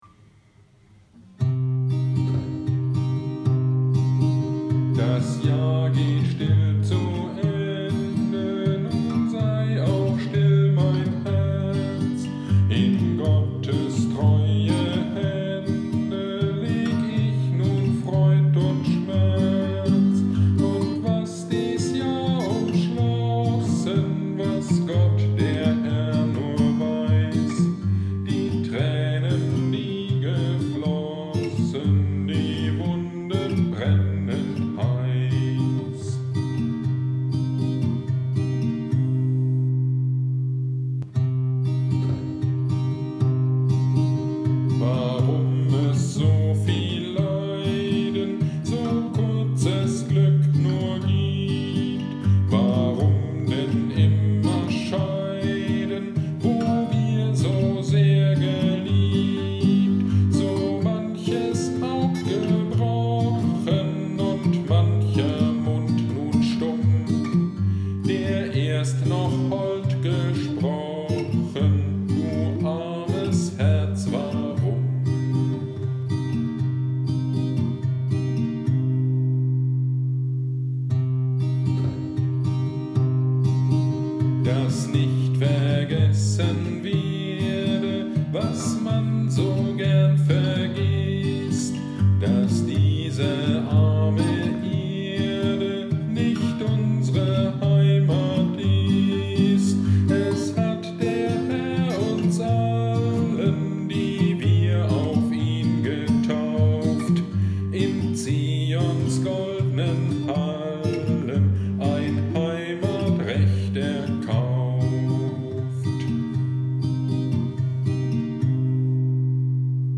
Predigt zum Altjahresabend 2020 - Kirchgemeinde Pölzig
Predigt-zu-Silvester-2020.mp3